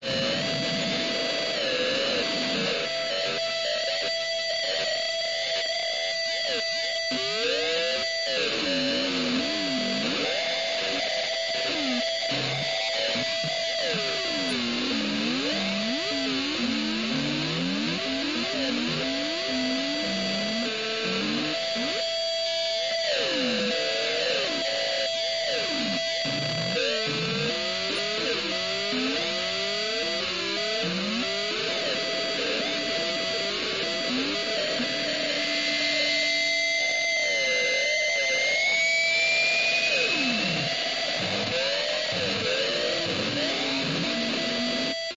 标签： 电子吉他 电子弓 踏板护罩
声道立体声